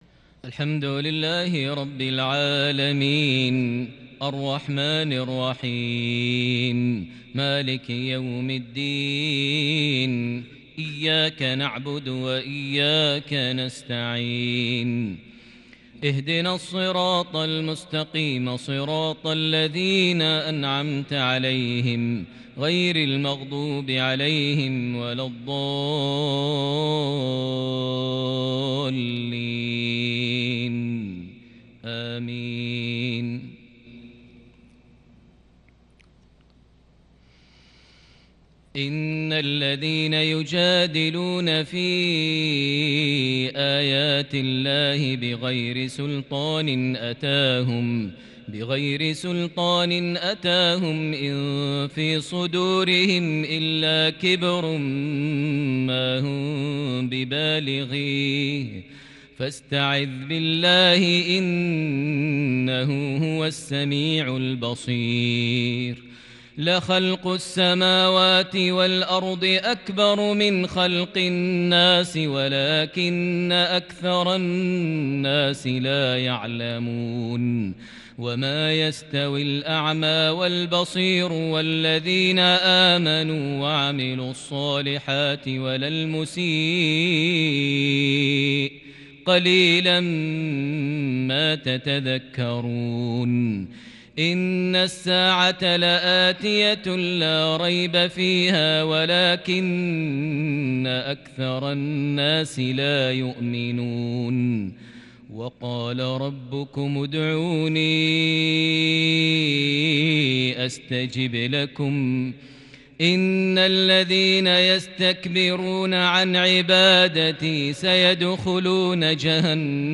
تلاوة خيالية فريده بالكرد من سورة غافر | عشاء 25 ذو الحجة 1441هـ (56-68) > 1441 هـ > الفروض - تلاوات ماهر المعيقلي